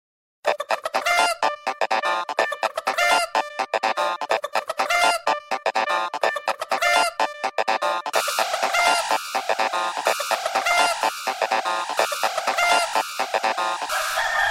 morning-alarm-remix_24724.mp3